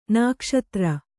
♪ nākṣatra